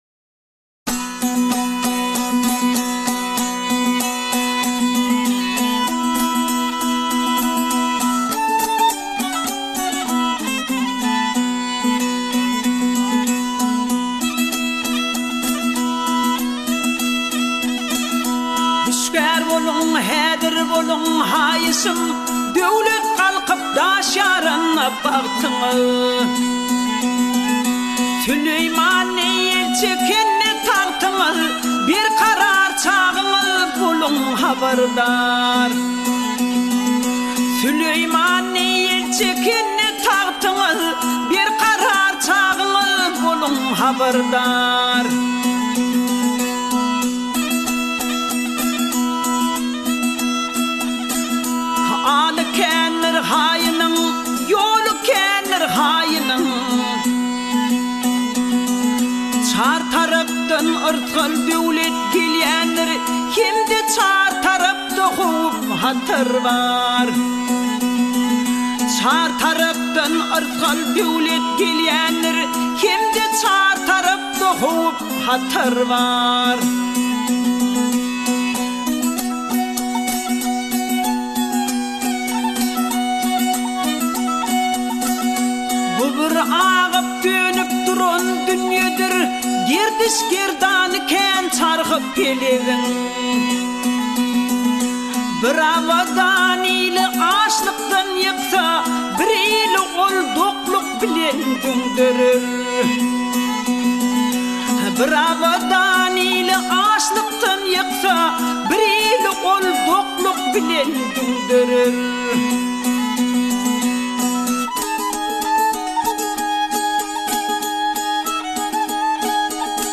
پنج آهنگ ترکمنی بسیار زیبا